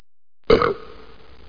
burp.mp3